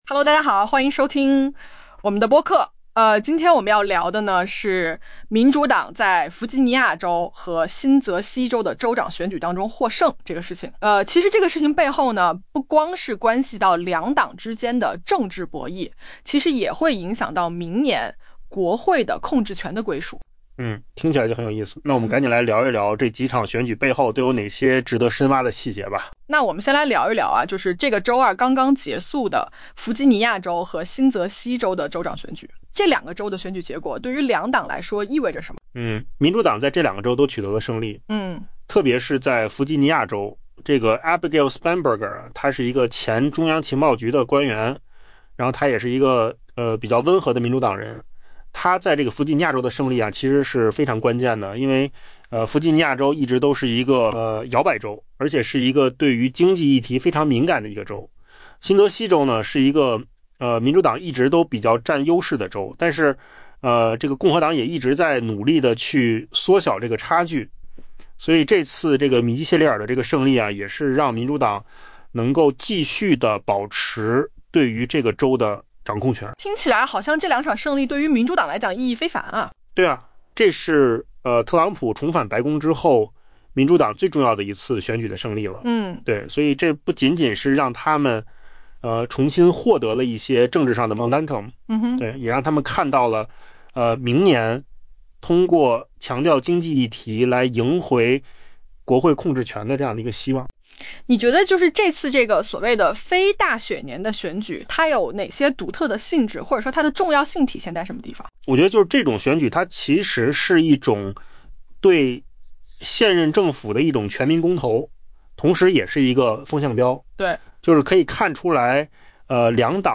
AI播客：换个方式听新闻 下载mp3 音频由扣子空间生成 民主党于周二在弗吉尼亚州和新泽西州的州长选举中获胜，重新夺回了政治势头。